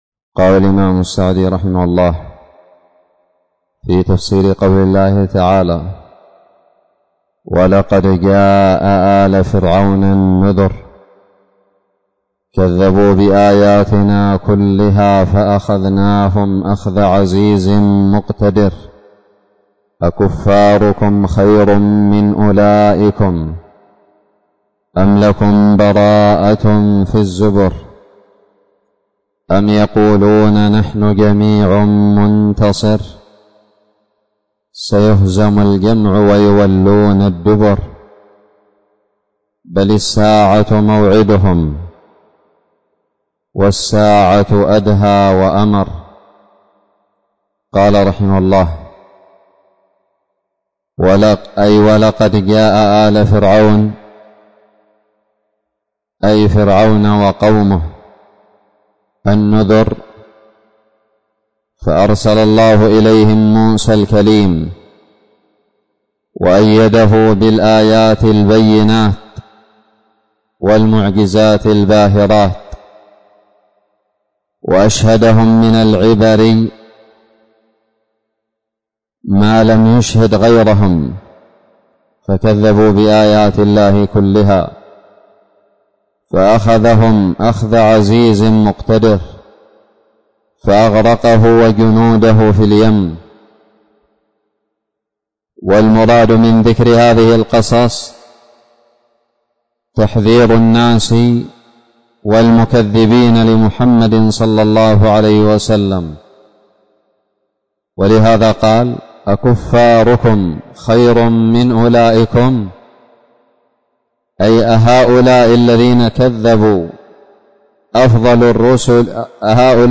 الدرس السابع من تفسير سورة القمر
ألقيت بدار الحديث السلفية للعلوم الشرعية بالضالع